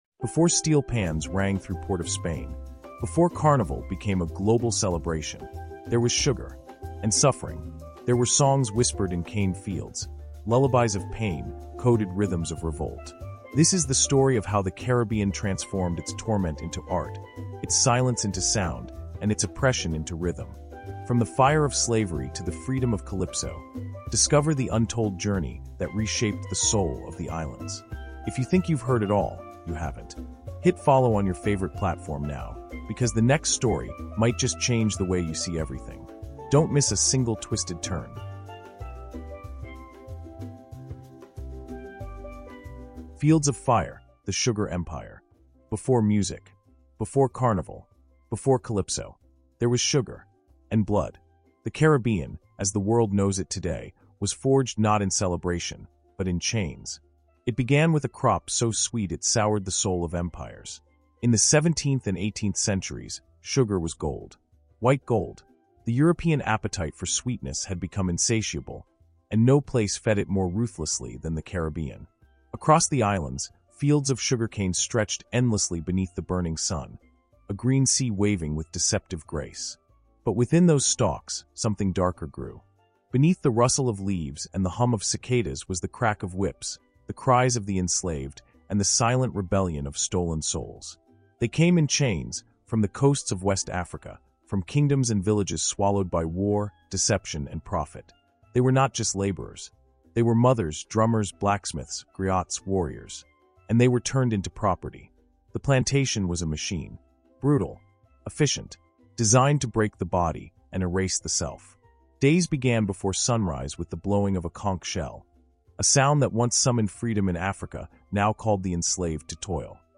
From Sugar to Calypso is a powerful, documentary-style Caribbean history podcast that traces the raw, untold story of the Caribbean’s transformation — from the violent plantation systems to the vibrant heartbeat of Caribbean music, literature, and cultural resilience.